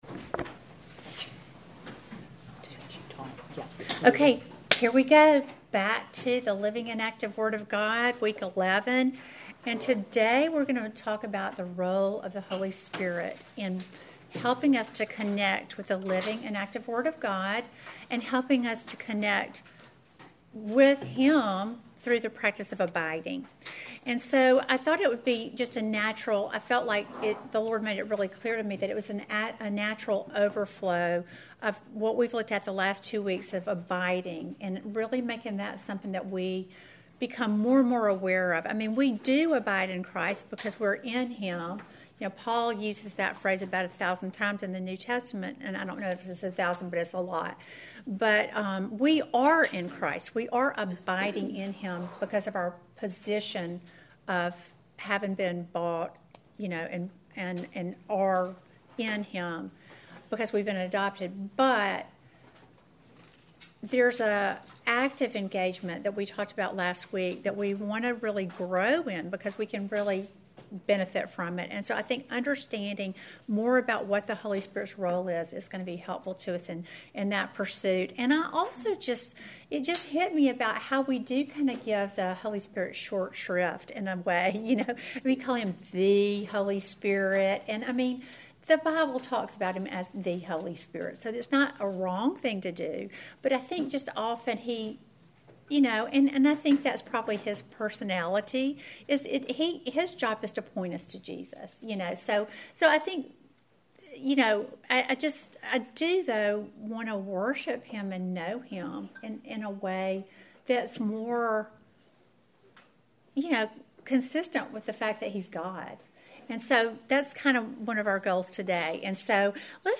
Welcome to the eleventh lesson in our series GOD’S LIVING AND ACTIVE WORD!
Due to a technical issue the recorded lesson is cut off at the end of the teaching; the testimony and prayer are missing.